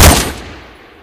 ru556_shoot.ogg